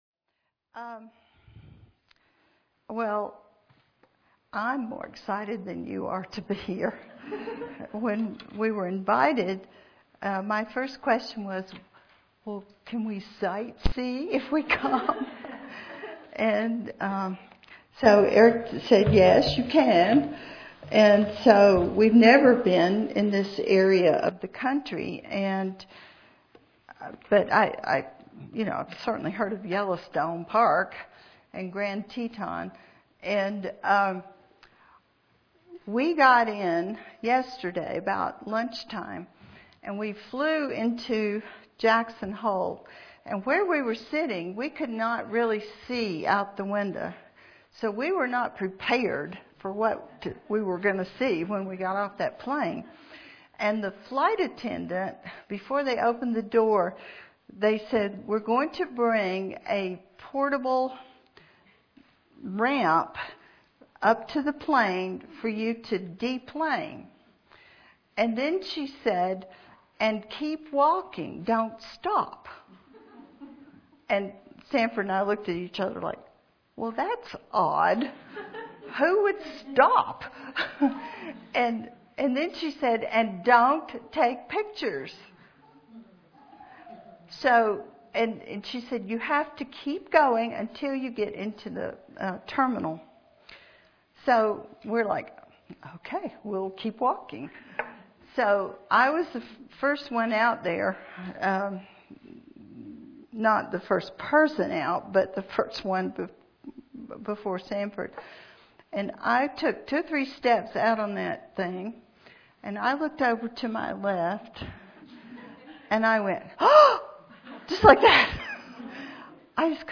Cornerstone Church and Teton Valley Bible Church Women’s Conference 2011: “Unfading Beauty”